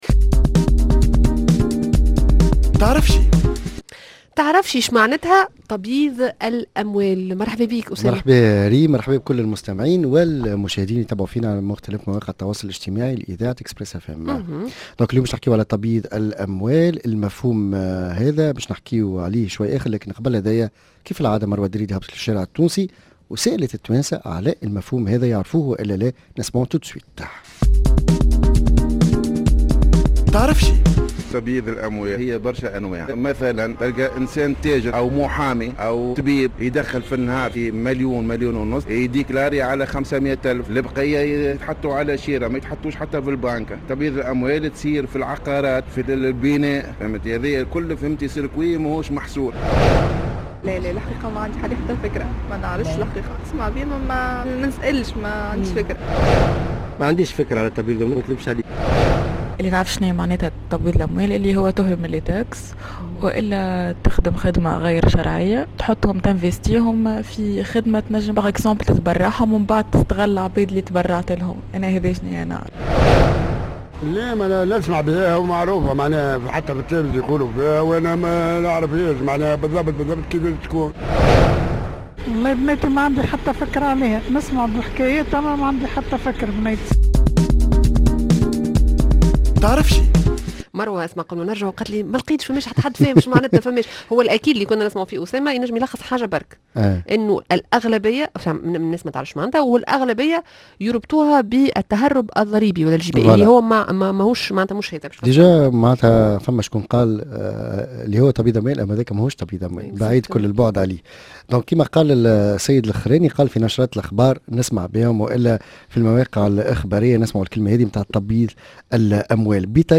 Micro Trottoir